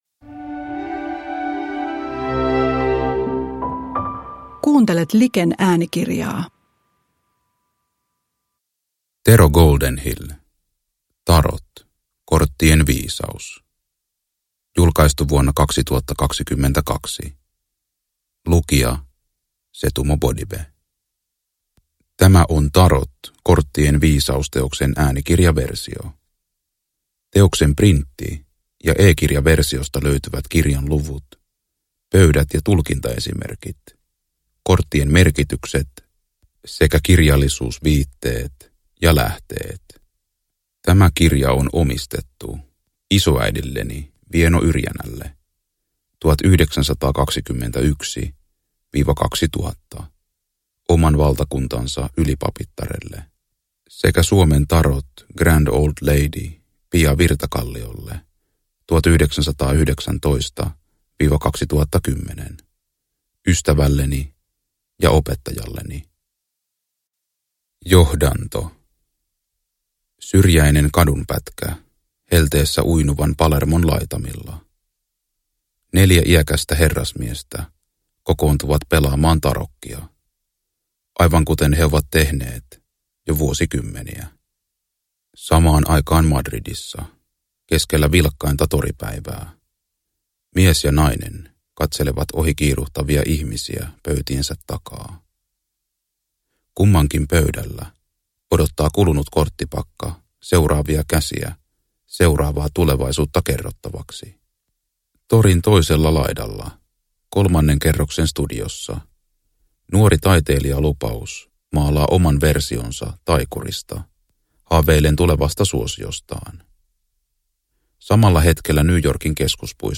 Tarot – Ljudbok – Laddas ner